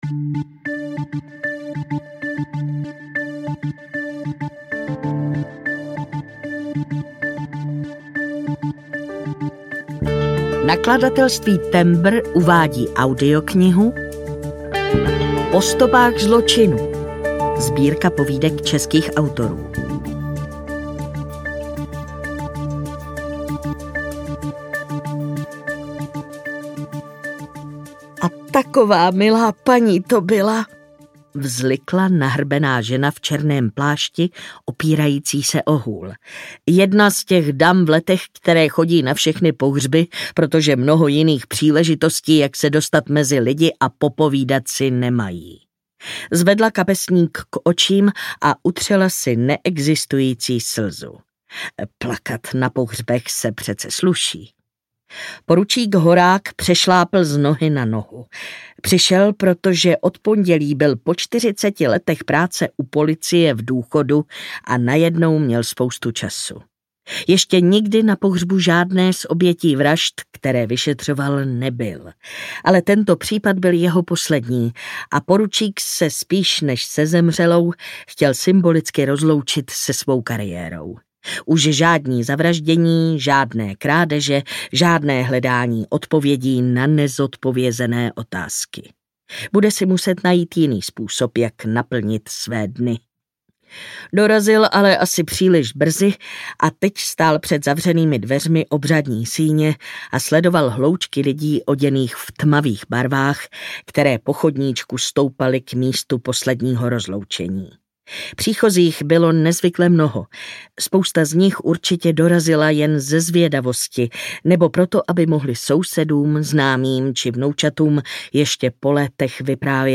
Po stopách zločinů audiokniha
Ukázka z knihy